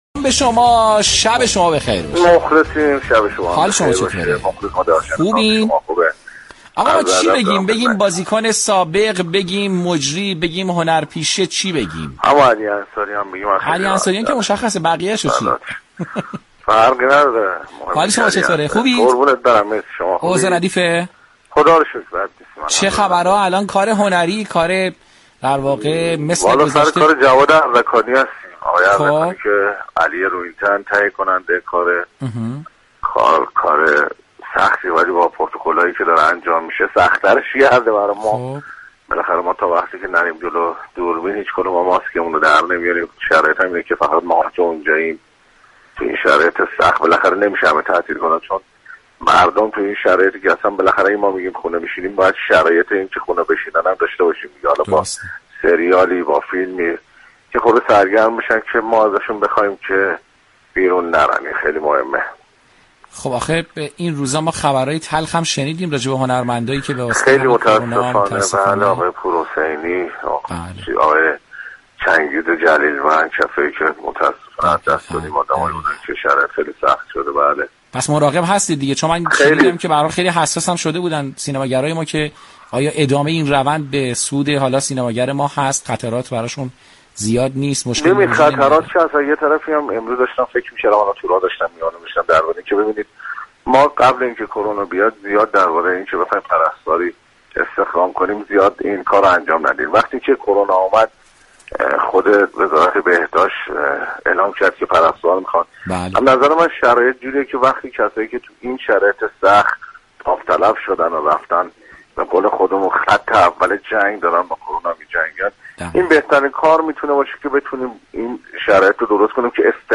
مصاحبه جالب و شنیدنی علی انصاریان دردفاع از مدافعان سلامت